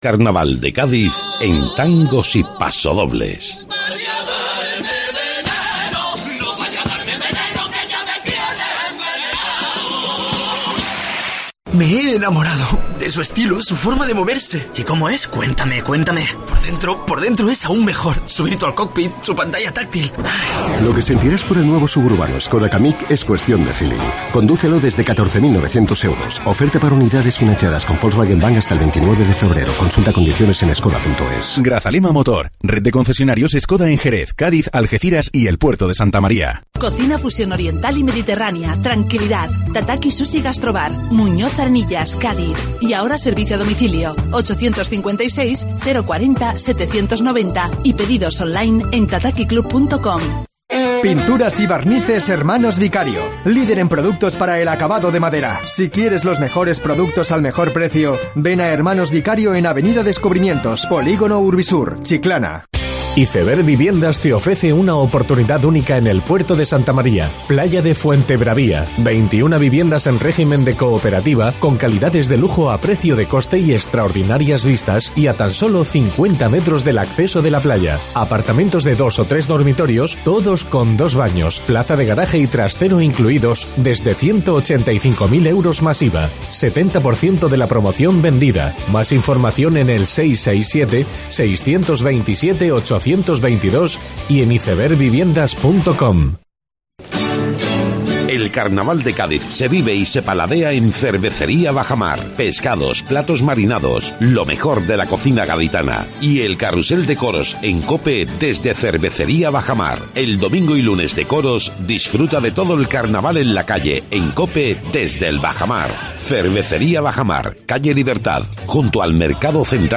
AUDIO: Escucha la entrevista a uno de los autores de la comparsa de la cantera